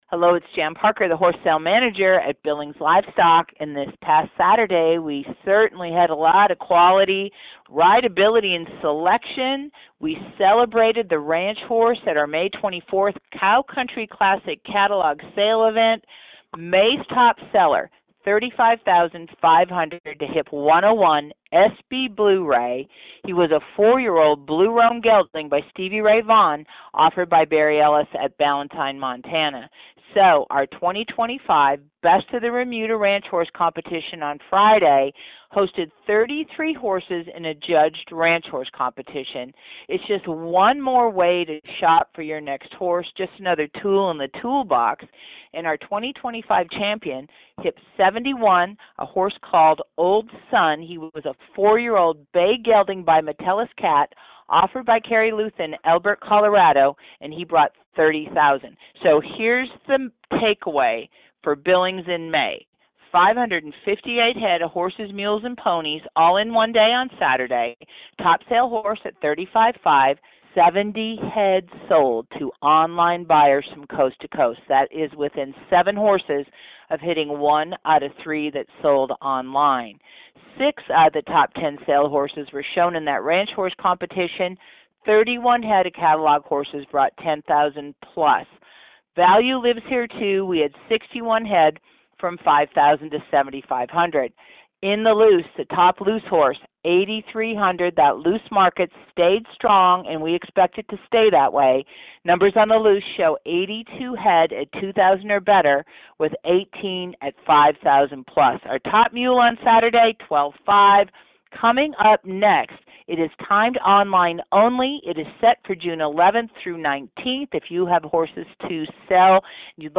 Click PLAY (right arrow above) to hear the latest BLS Horse Sale Market Report